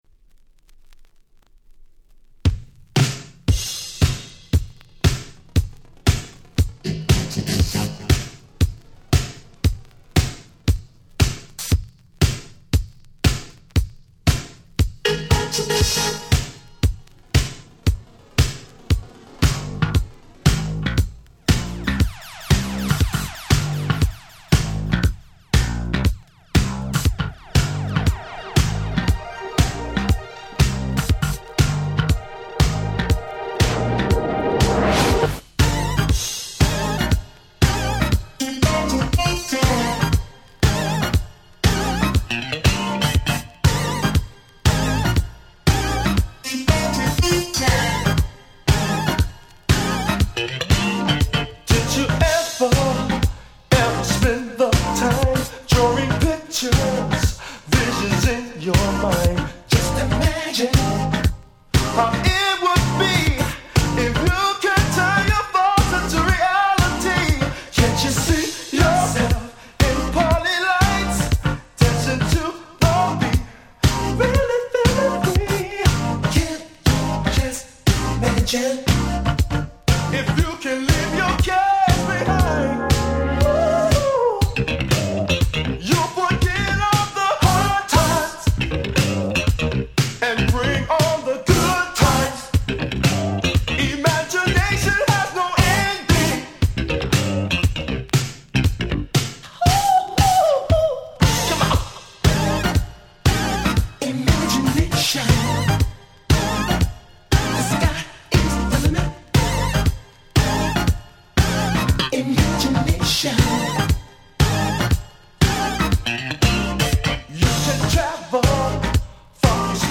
82' Big Hit Disco/Dance Classic !!
日本のDiscoでも凄く流行った鉄板Disco Boogie！